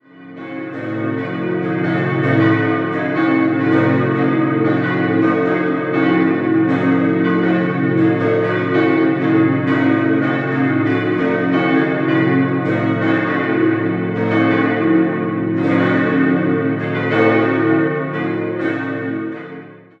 Idealquartett: b°-des'-es'-ges' Die vier Stahlglocken wurden 1949 vom Bochumer Verein für Gussstahlfabrikation gegossen.